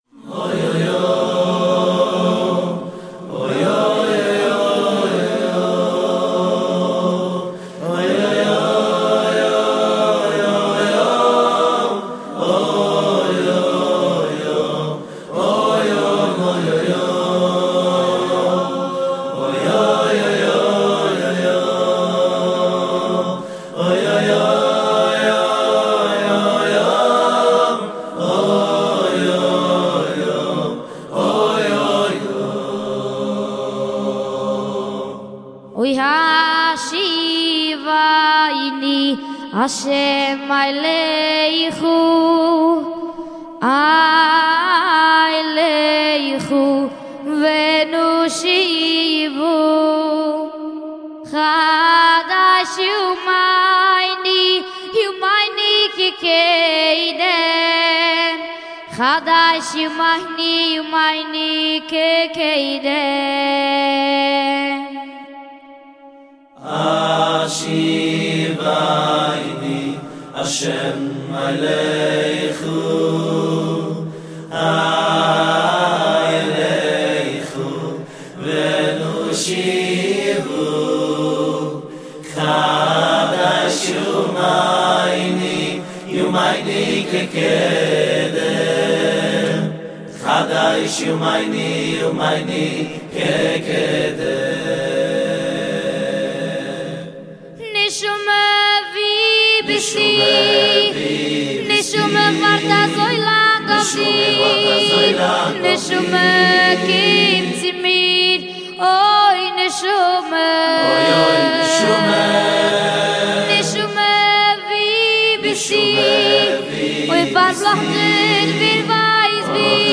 גרסת האקפלה